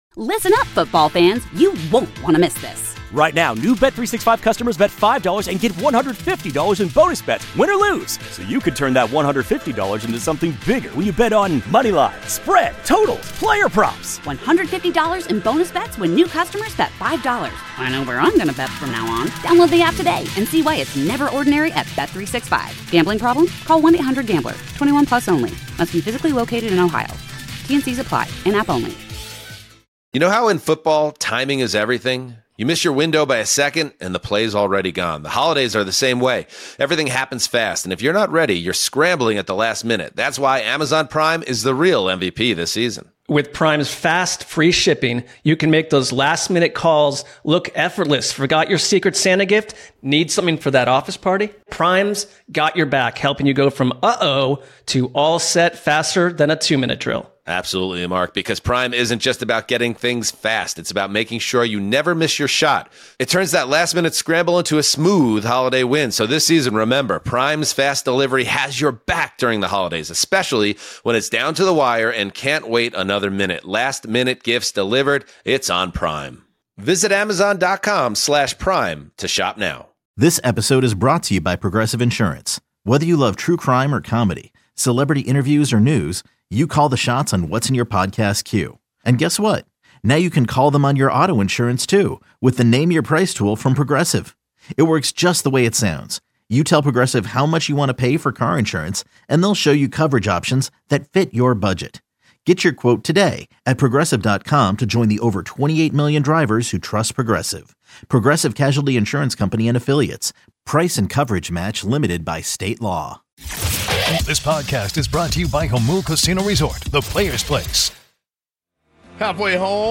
In-Studio!